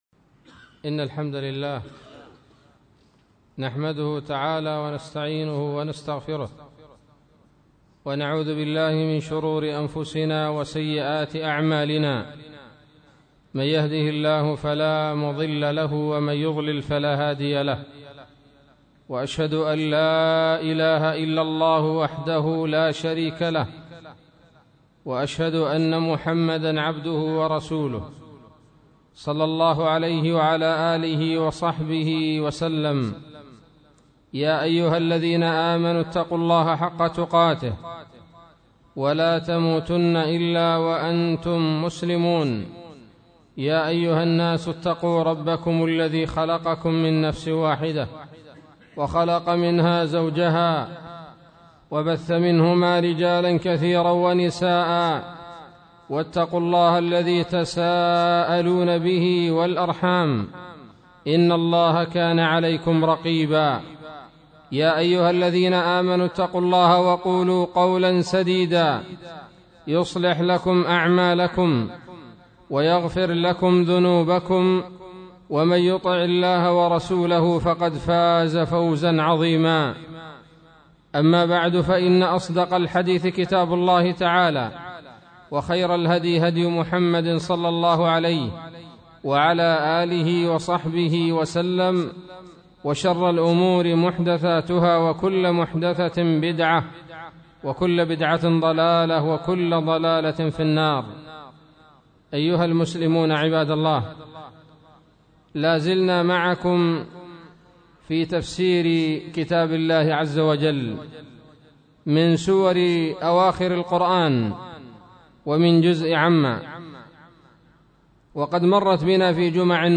خطبة بعنوان : ((تفسير سورة الفيل